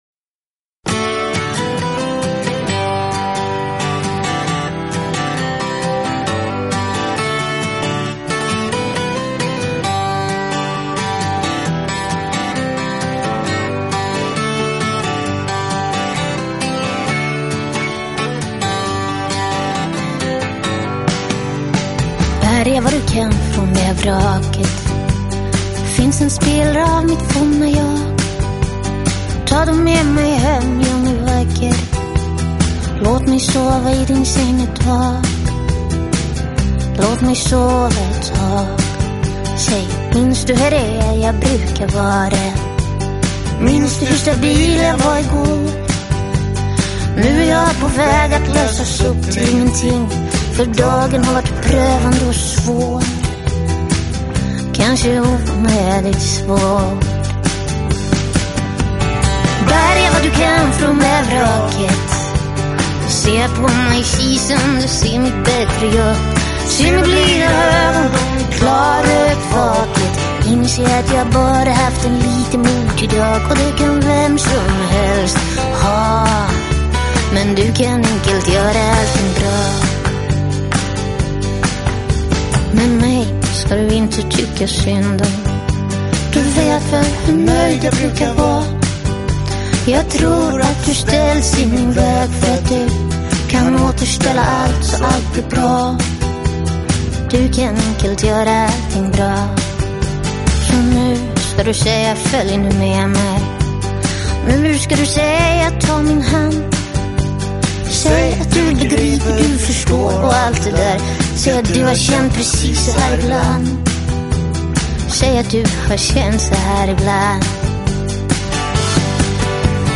【爵士美声】
于丹麦乡间、北欧著名的录音室PUK灌录
流行与爵士双栖女伶，担任全专辑之作曲/作词/演唱
喜爱法式民谣摇滚不能错过之专辑